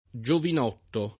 giovinotto [ J ovin 0 tto ]